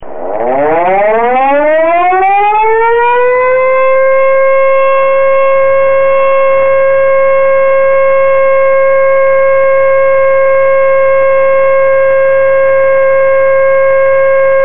Serie: ELECTROSIRENAS DIRECCIONALES GRAN POTENCIA ACÚSTICA
Sirena electromecánica de motor trifásico
2,2KW - 135dB